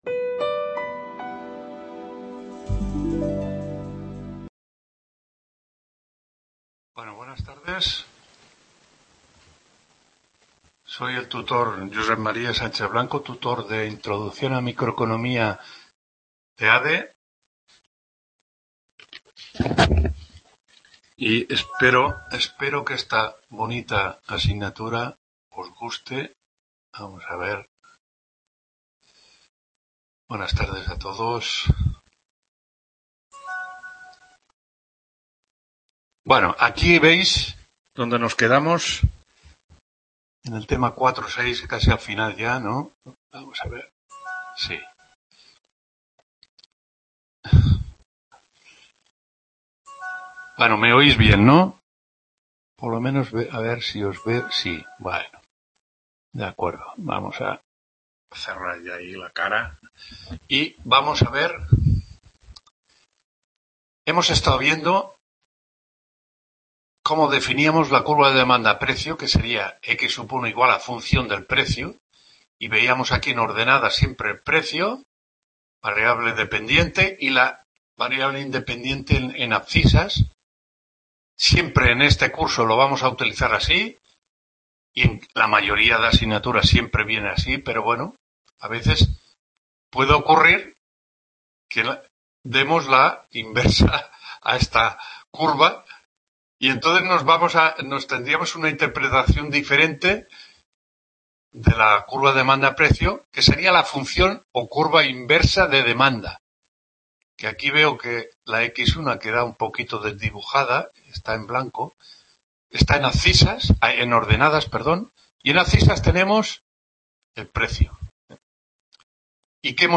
9ª TUTORÍA INTRODUCCIÓN A LA MICROECONOMÍA (ADE)15-12… | Repositorio Digital